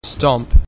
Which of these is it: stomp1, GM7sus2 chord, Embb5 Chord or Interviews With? stomp1